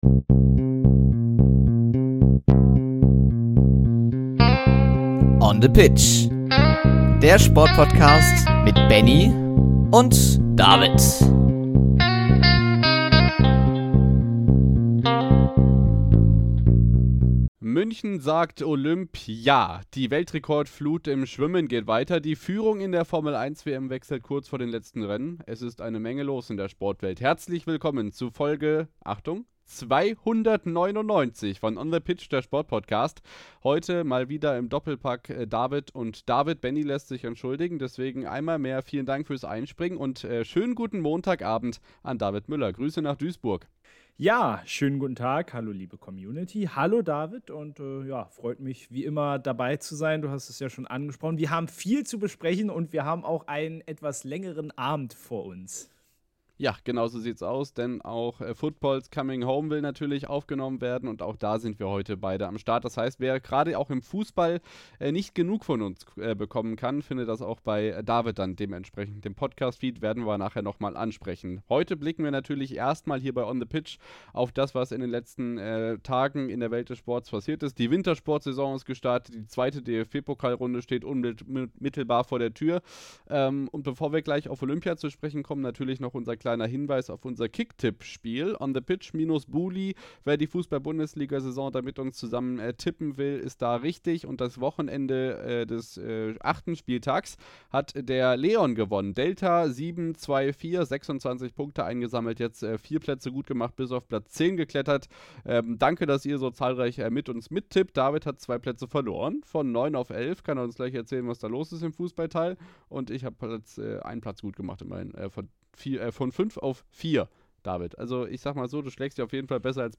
Außerdem: Der Winter ist zurück – Sölden eröffnet die Ski-Saison und in Klingenthal geht der Sommer-Grand-Prix mit einem deutschen Gesamtsieg zu Ende. Dazu alles zum DFB-Pokal, Handball-Reformen, NBA-Start und Zverevs Wien-Finale sowie vielen weiteren Themen der letzten Tagen. Der kompakte Wochenrückblick diesmal wieder im Duo